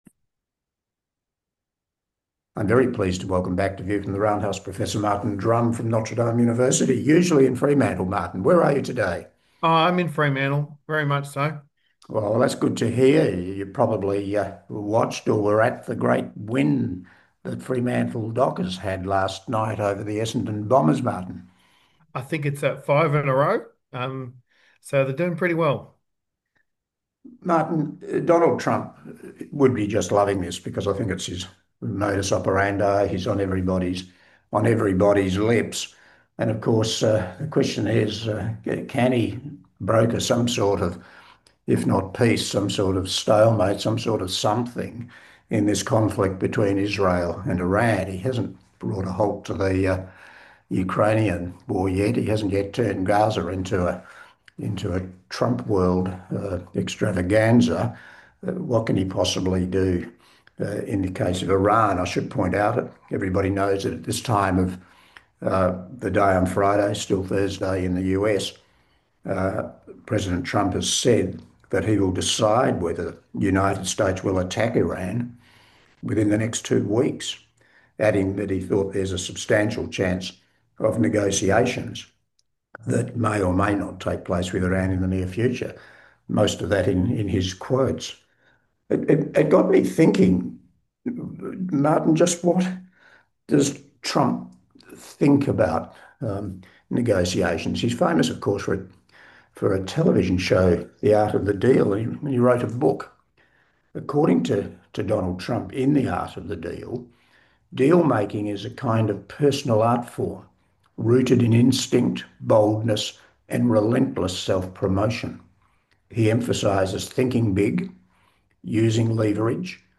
Welcome back to our politics podcast